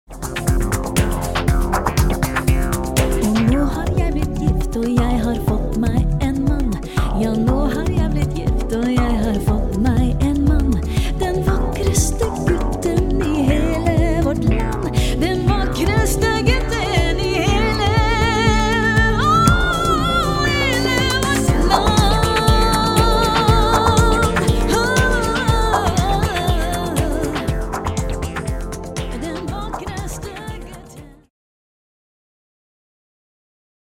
studio in Karachi May '05